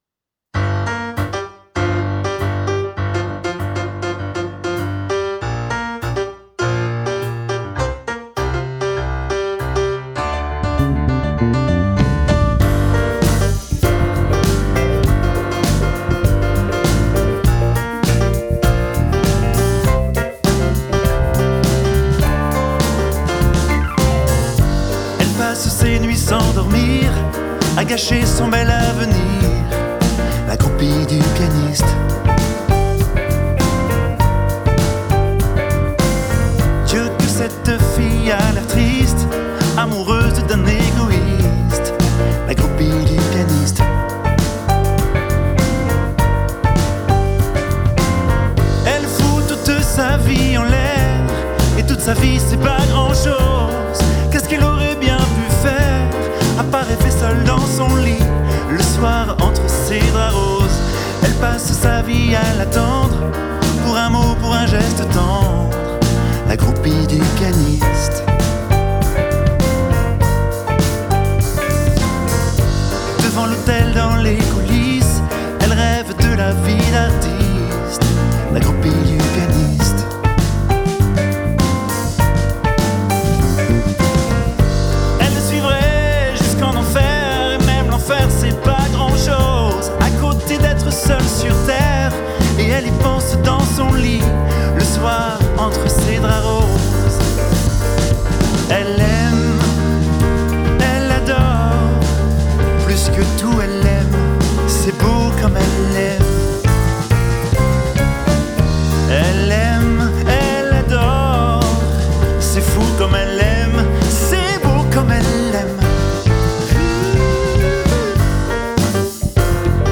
Guitares additionnelles